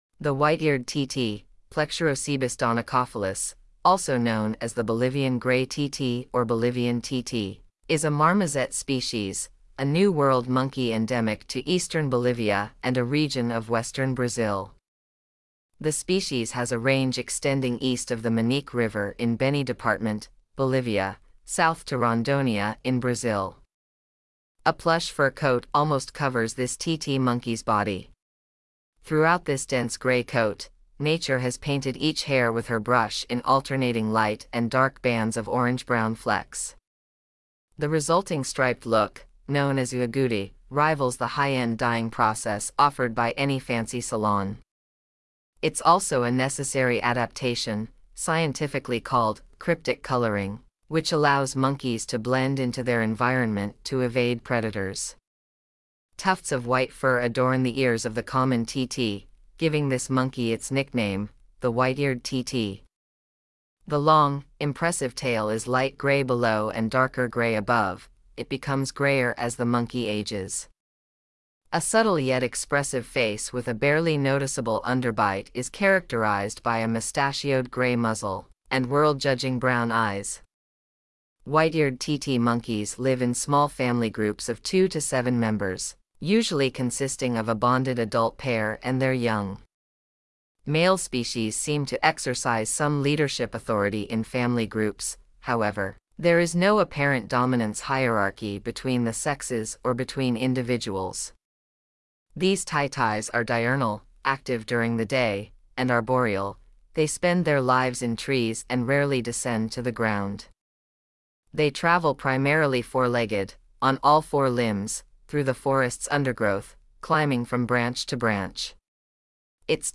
White-eared Titi
White-eared-Titi.mp3